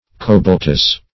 Cobaltous \Co*balt"ous\, a. (Chem.)